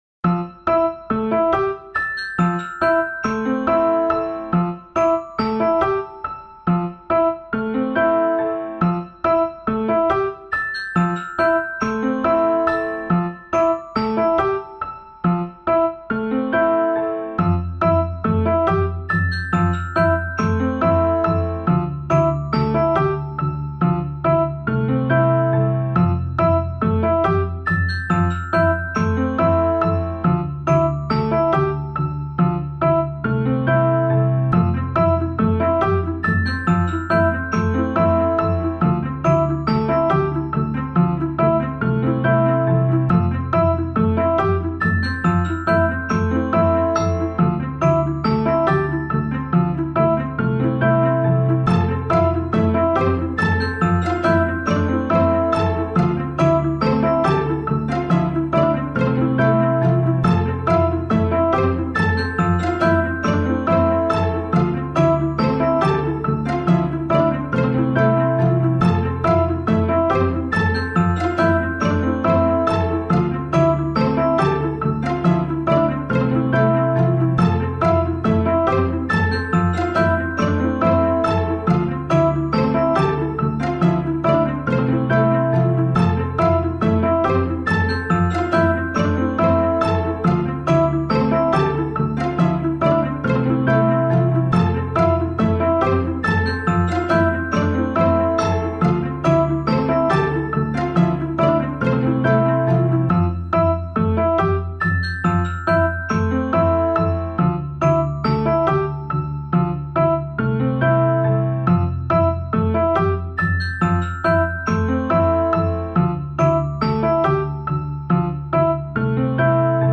Collections of melancholic and minimalistic soundscapes.